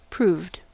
Ääntäminen
Synonyymit proven Ääntäminen US Tuntematon aksentti: IPA : /ˈpruːvd/ Haettu sana löytyi näillä lähdekielillä: englanti Proved on sanan prove partisiipin perfekti.